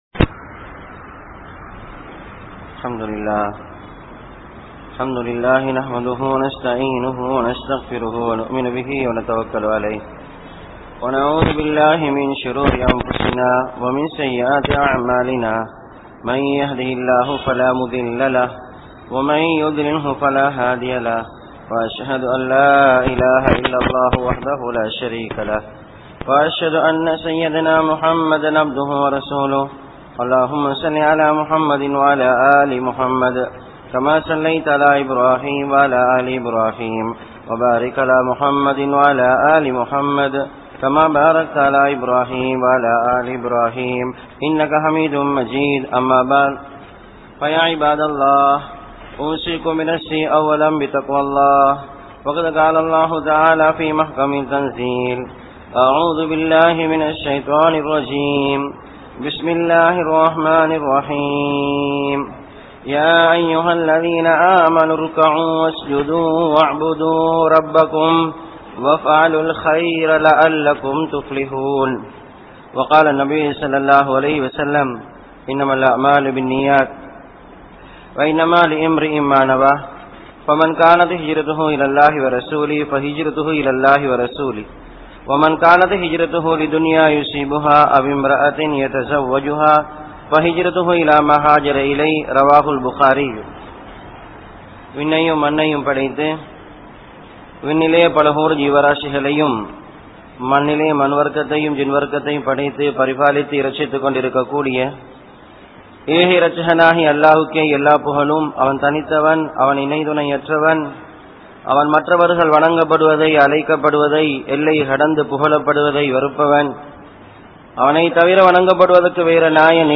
Ibathath | Audio Bayans | All Ceylon Muslim Youth Community | Addalaichenai